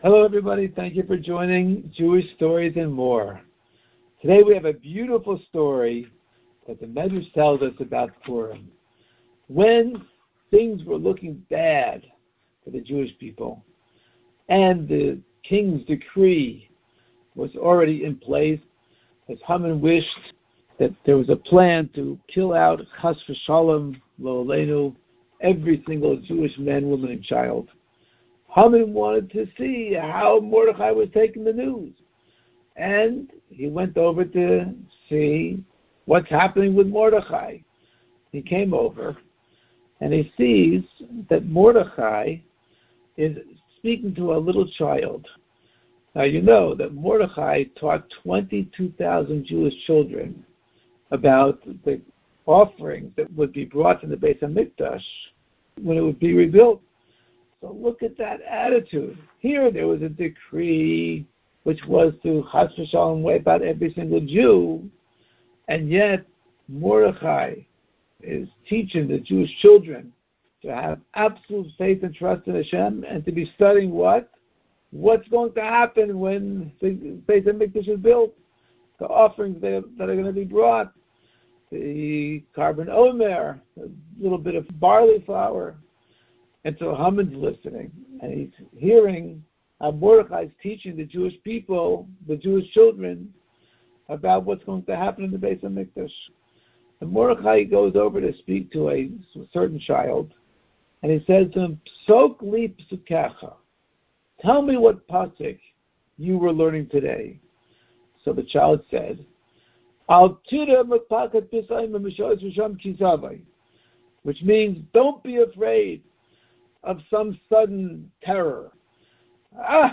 Story time for kids